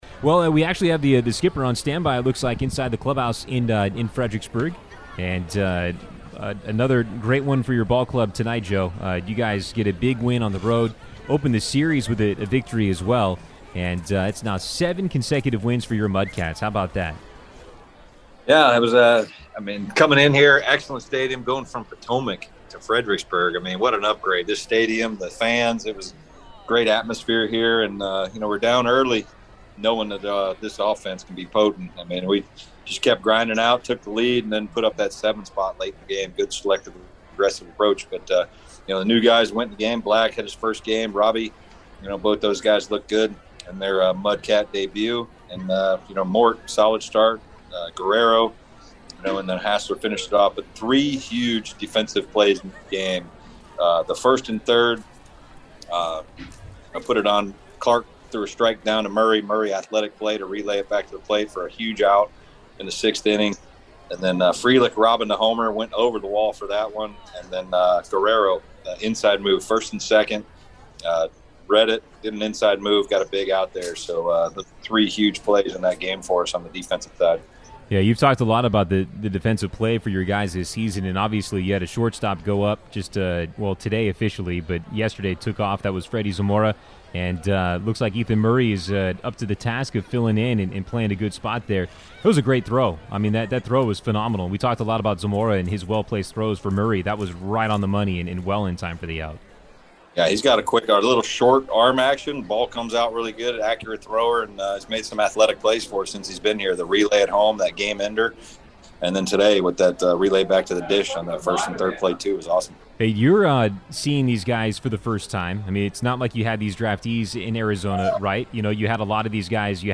AUDIO: Post-Game Interview